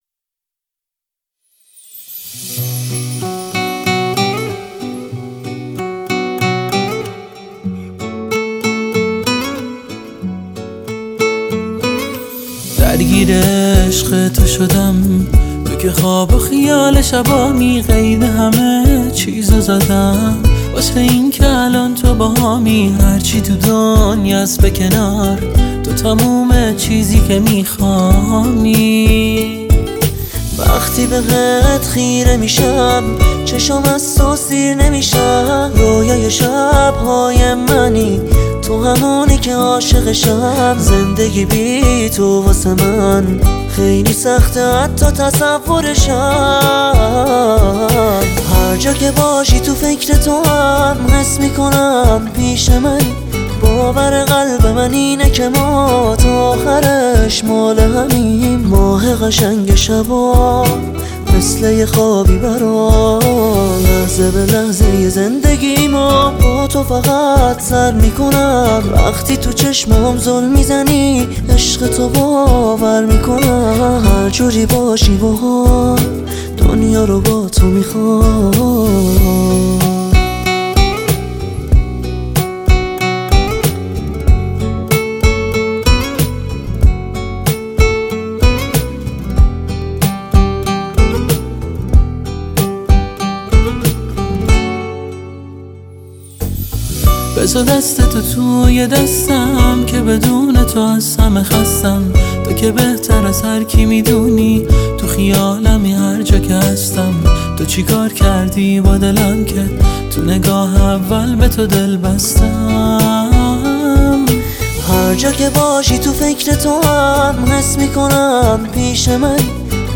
Unplugged Version